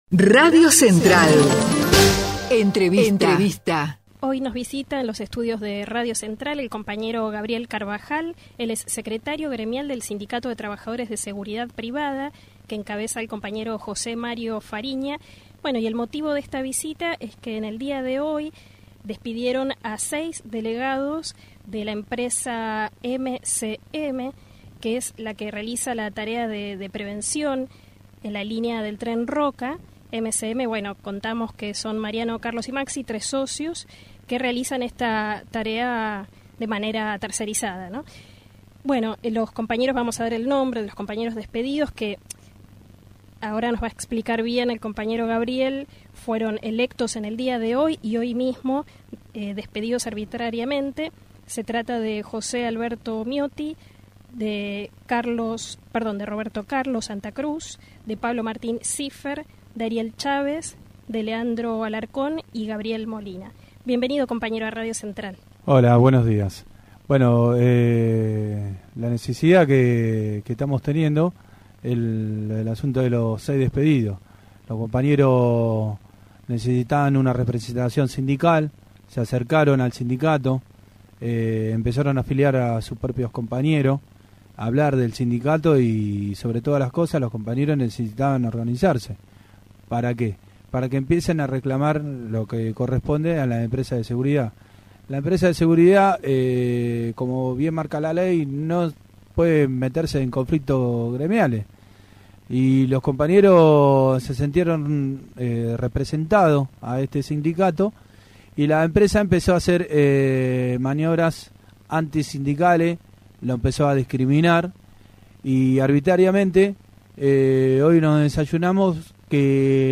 Entrevista a los delegados gremiales